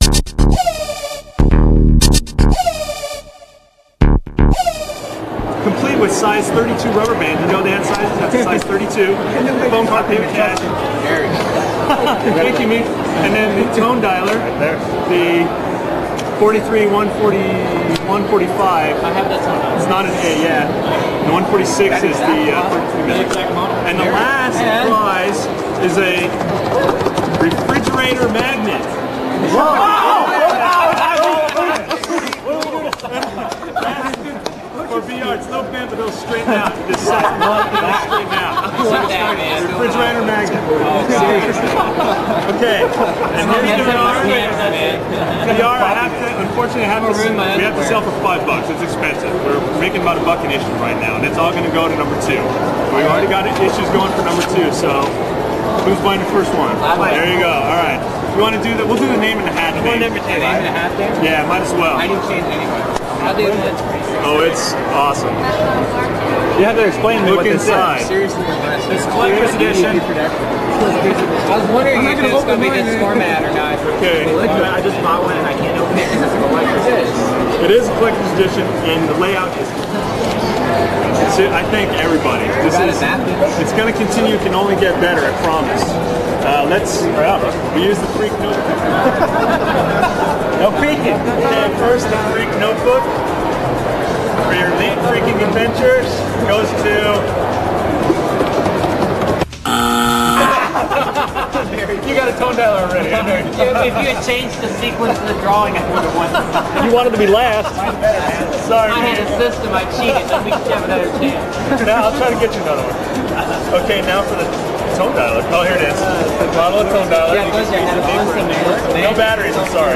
The premier of BinRev and a fun prize give-a-way, all at a 2600 meeting.